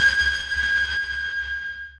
sonarTailWaterMediumShuttle3.ogg